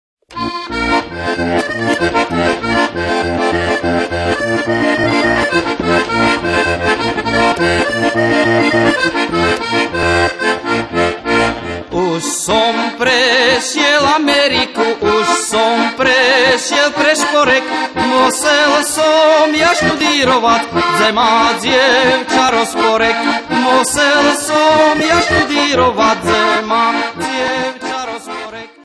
Kategória: Ľudová hudba